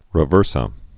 (rĭ-vûrsə)